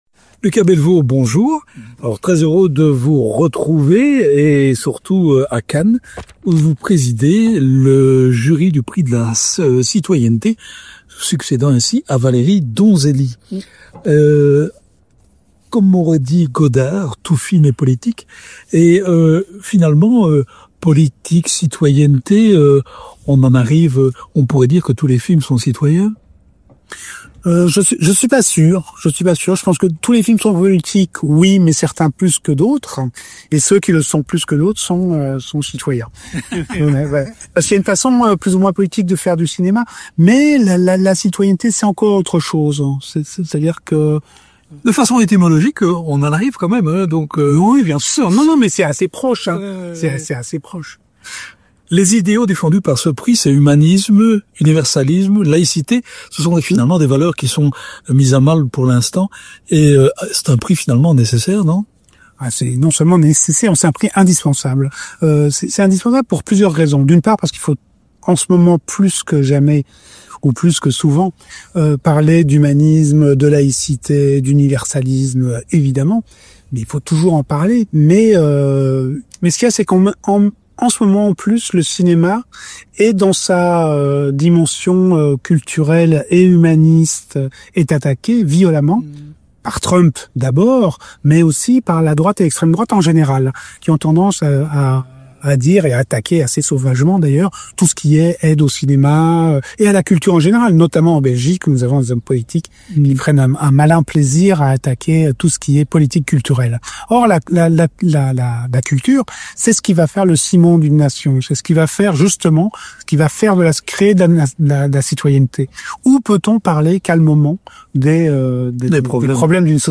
Notre compatriote est sur la Croisette non pour défendre sa dernière création (son prochain film « Les Tourmentés », adaptation de son roman éponyme ne sortira qu’en septembre prochain) mais pour présider le Jury du Prix de la Citoyenneté 2025, succédant ainsi à la réalisatrice Valérie Donzelli. Rencontre.